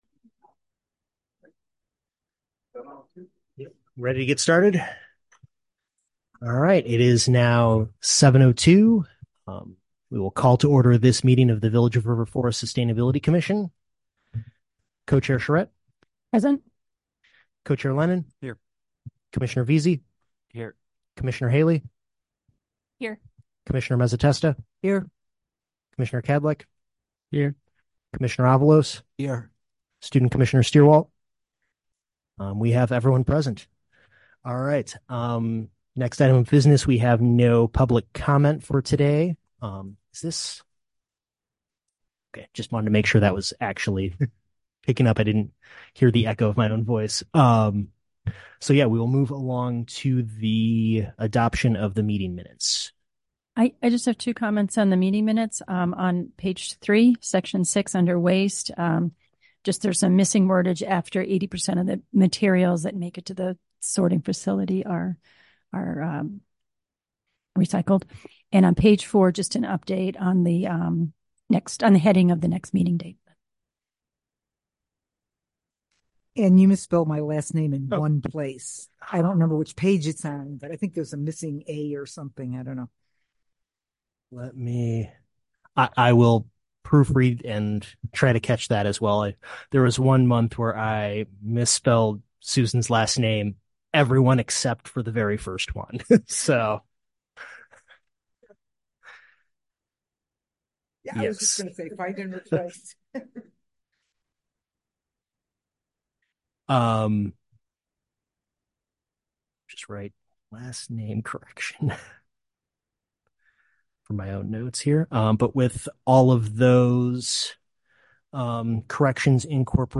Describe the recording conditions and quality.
Village Hall - 400 Park Avenue - River Forest - IL - COMMUNITY ROOM